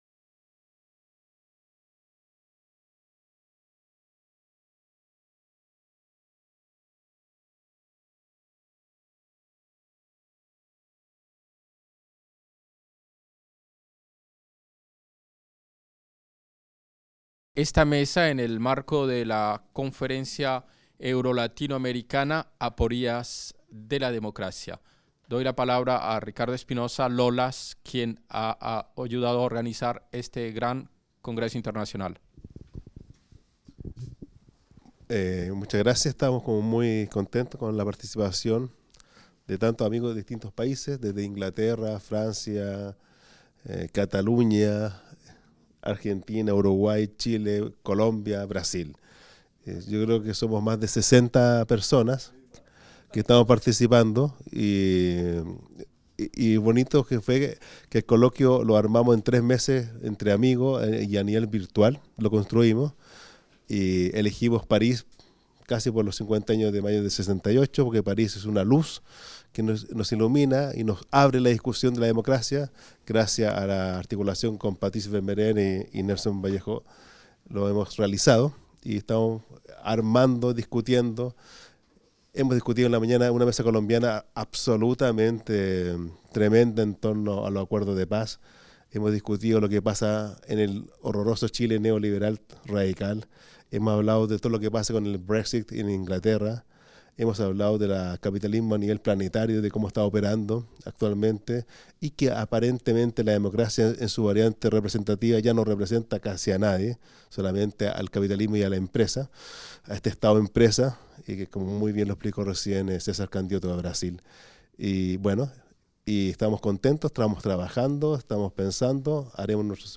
Apories de la démocratie | Table ronde Catalogne-démocratie | Canal U
1er Colloque international euro-latino-américain Du 29 novembre au 1er décembre 2017, s'est tenu à la Fondation Maison des sciences de l'homme le premier colloque international euro-latino-américain consacré aux apories de la démocratie.